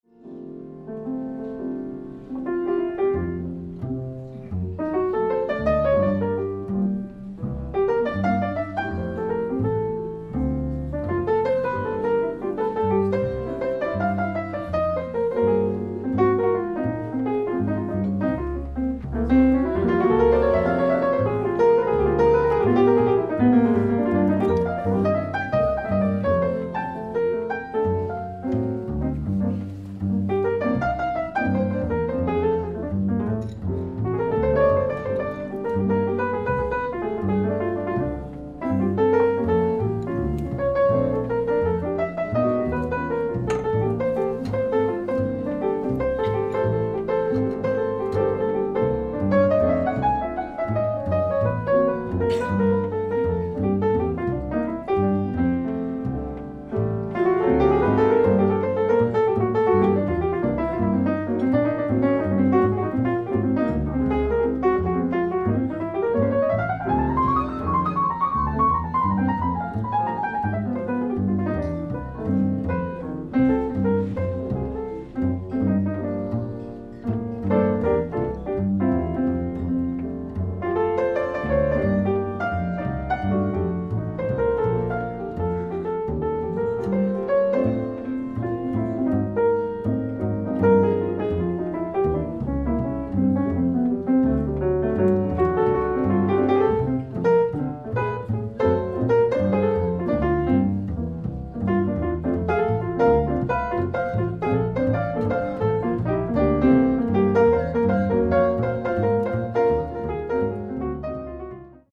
ディスク１＆２ ライブ・アット・レガッタバー、ケンブリッジ、マサチューセッツ 04/11/2002
※試聴用に実際より音質を落としています。